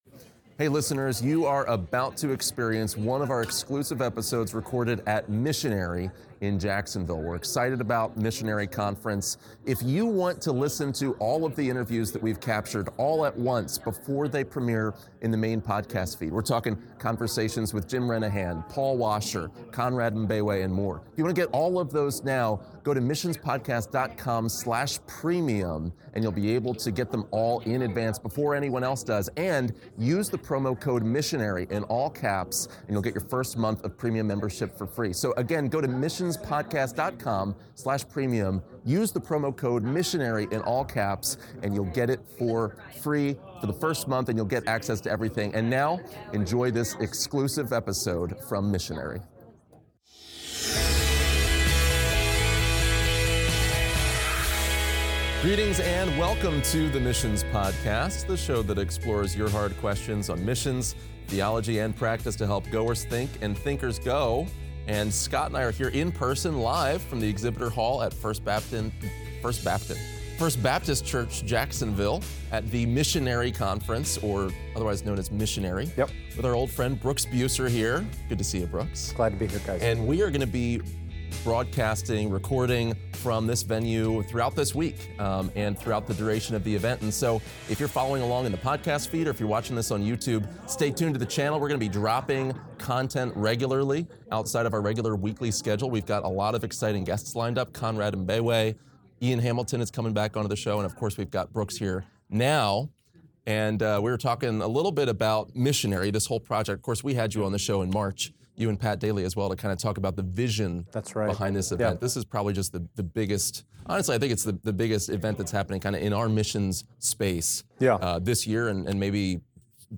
In this exclusive interview from Missionary